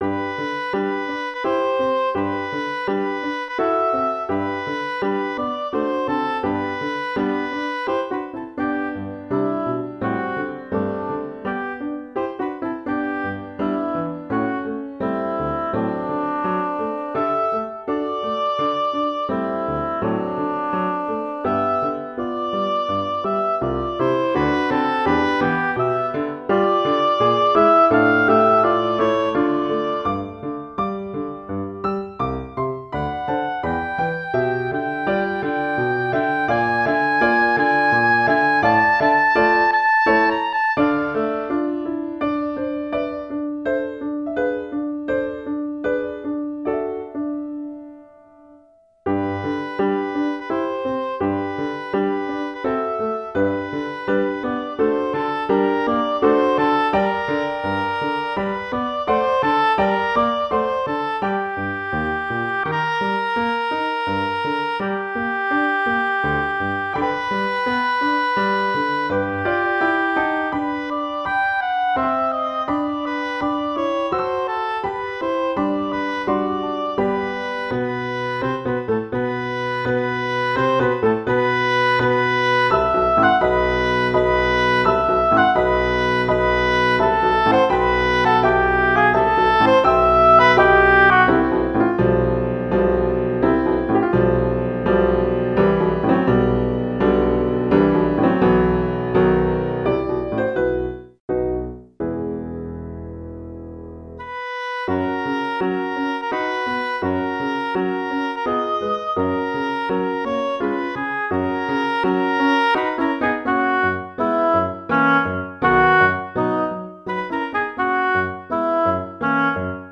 arranged for oboe and piano